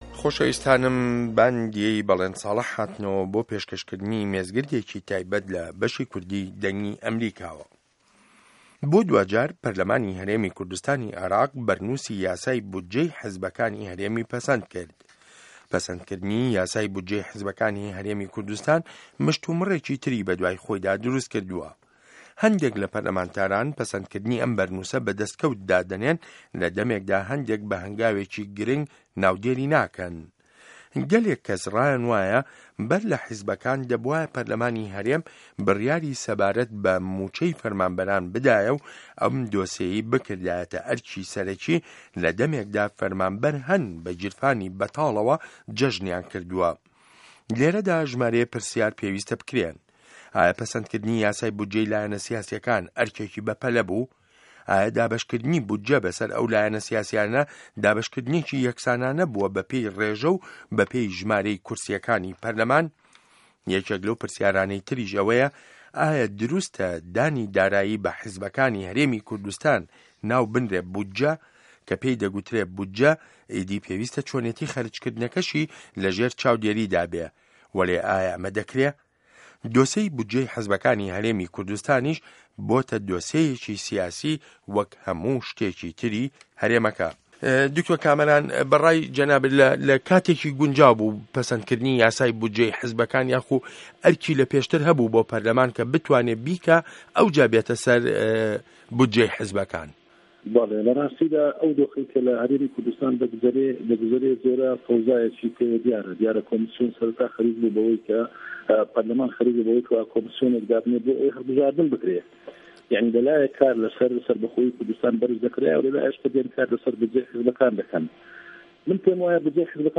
مێزگرد: یاسای بودجه‌ی حیزبه‌کانی کوردستان